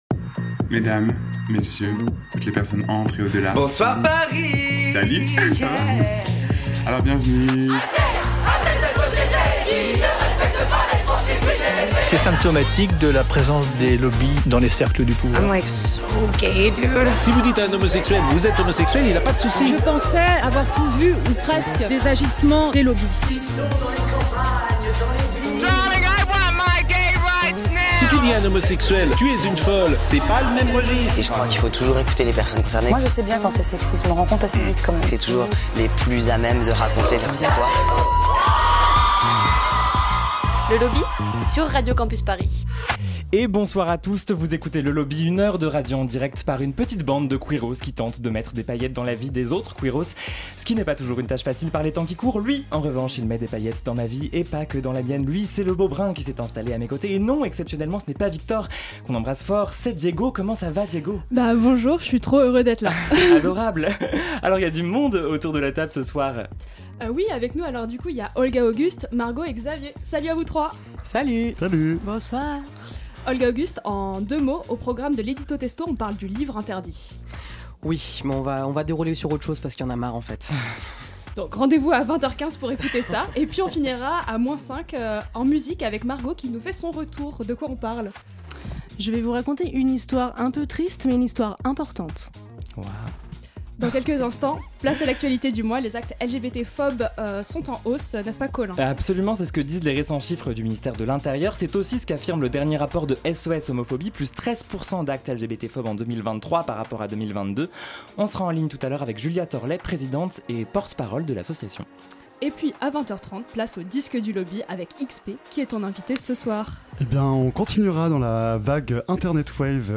Elle est notre invitée.